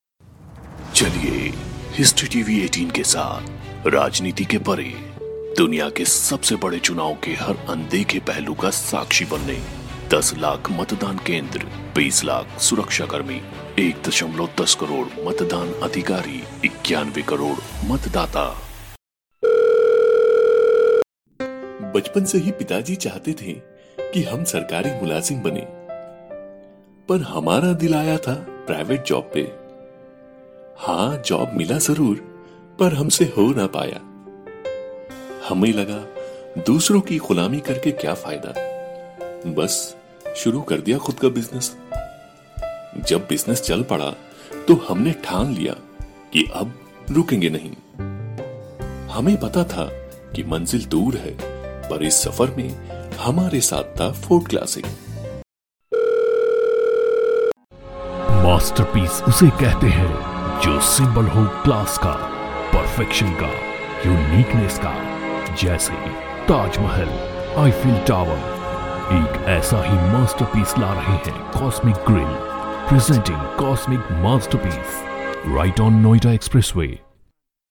宏亮大气【旁白解说】